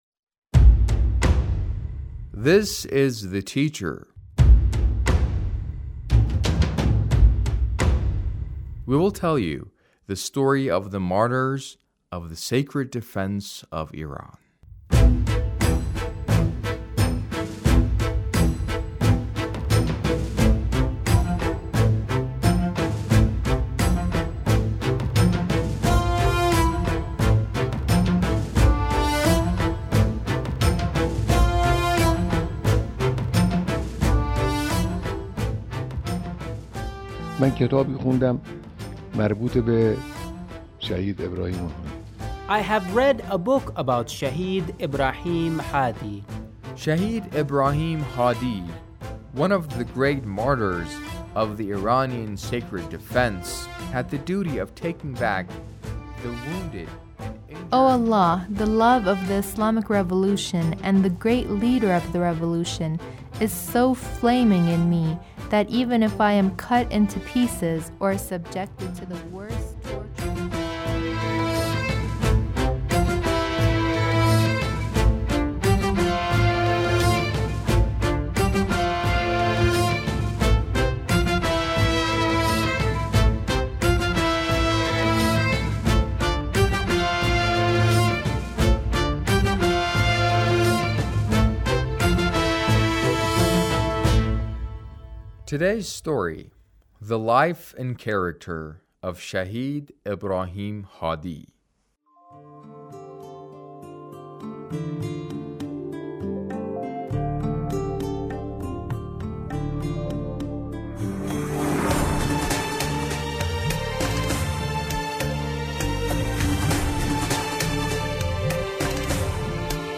A radio documentary on the life of Shahid Ibrahim Hadi- Part 2